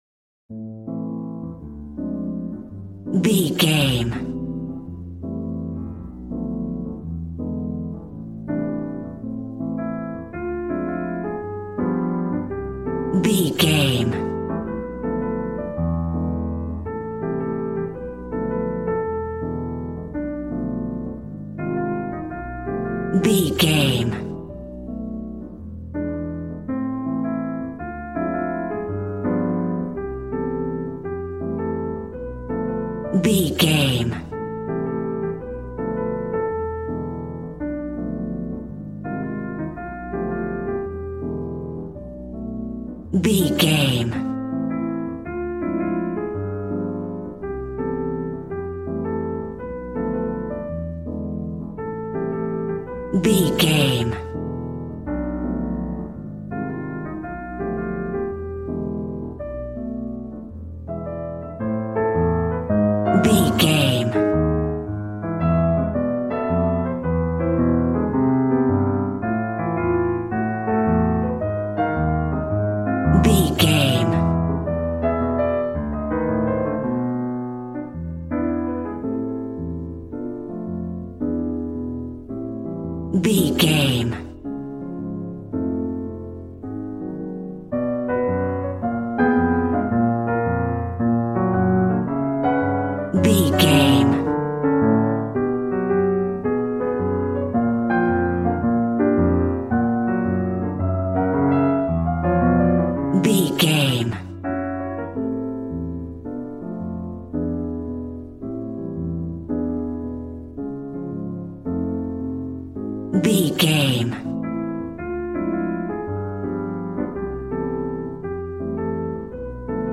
Smooth jazz piano mixed with jazz bass and cool jazz drums.,
Aeolian/Minor